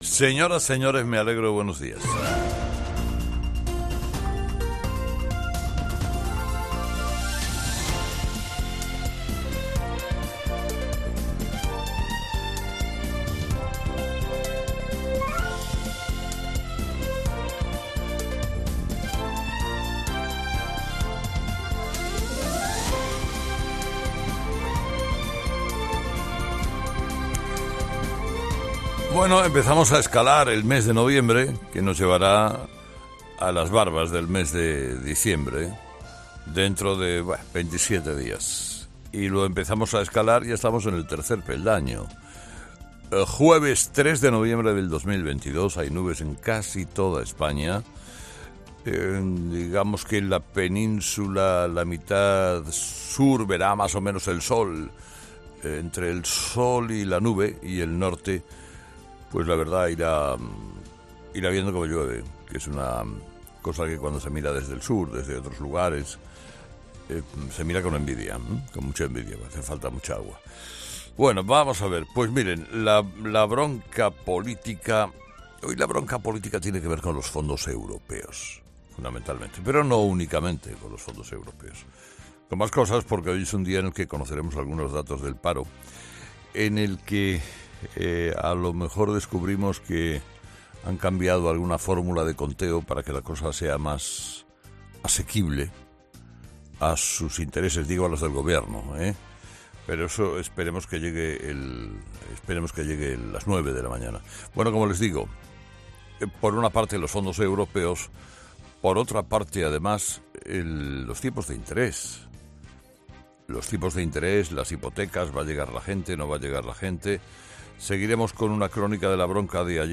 Carlos Herrera, director y presentador de 'Herrera en COPE', ha comenzado el programa de este miércoles analizando las principales claves de la jornada, que pasan, entre otros asuntos, por la justificación ante Europa de cómo se están repartiendo los fondos europeos que recibió España de Bruselas conocidos como 'Next Generation'.